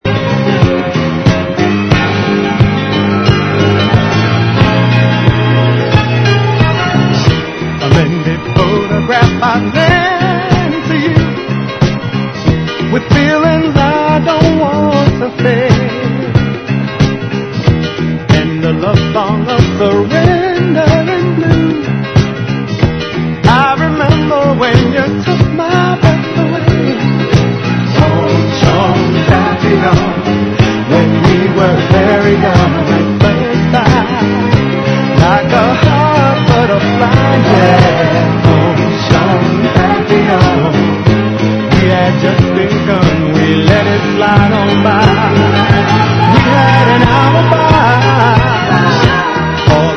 Urban | レコード / vinyl 12inch | EX | -